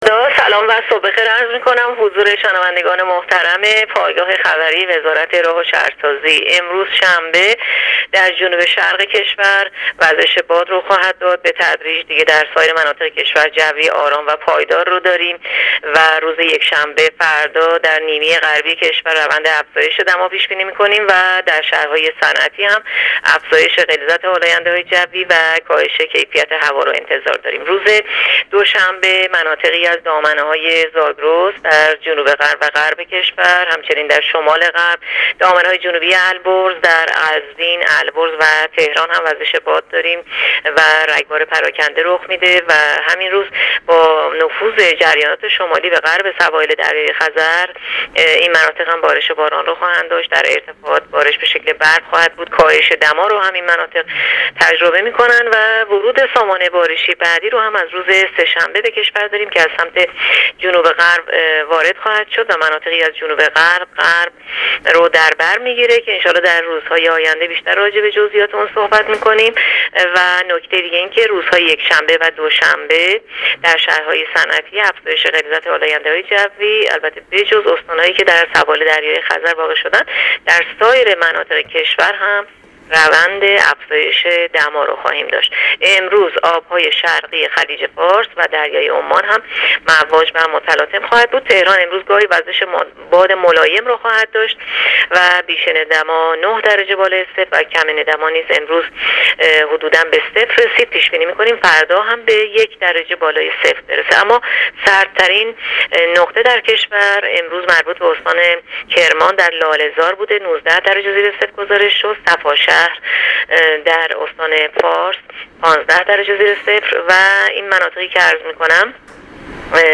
گزارش رادیو اینترنتی پایگاه‌ خبری از آخرین وضعیت آب‌وهوای ۱۵ دی؛